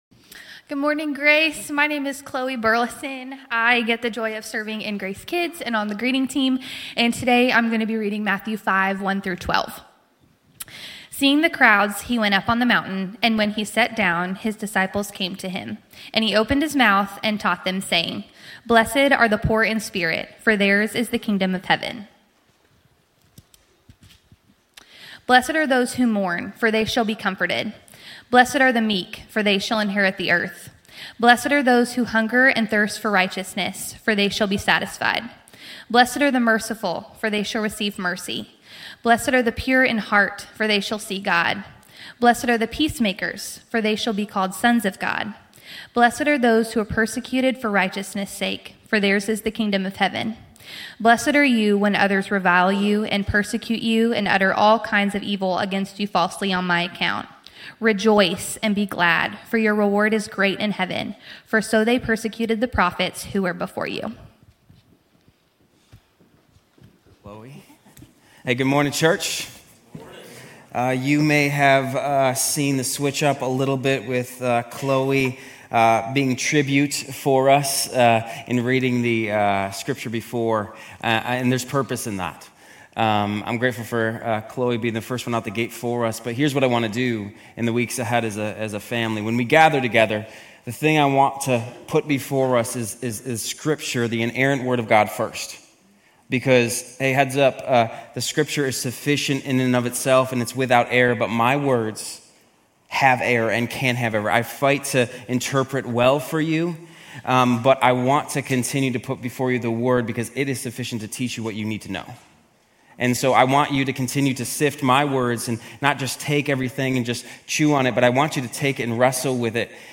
Grace Community Church University Blvd Campus Sermons 1_26 Sermon on the Mount Jan 27 2025 | 00:36:55 1x 00:00 / 00:36:55 Subscribe Share RSS Feed Share Link Embed